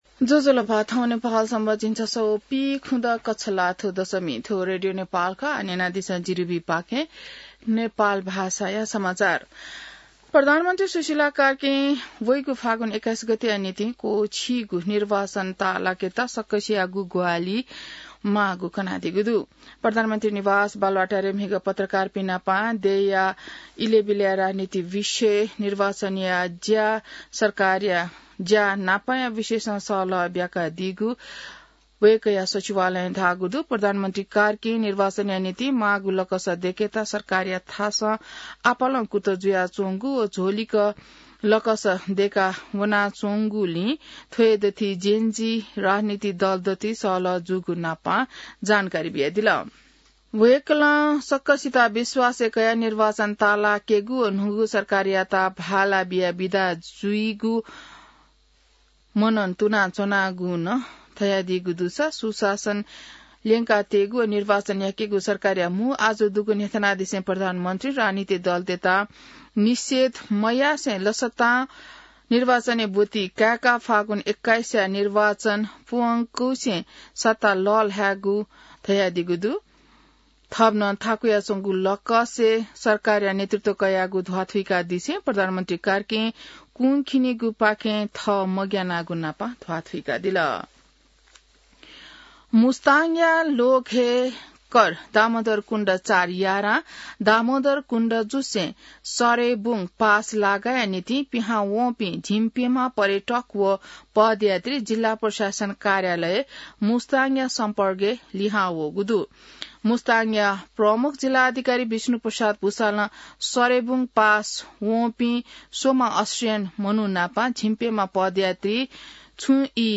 नेपाल भाषामा समाचार : १५ कार्तिक , २०८२